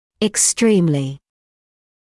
[ɪks’triːmlɪ][икс’триːмли]чрезвычайно, крайне, в высшей степени; очень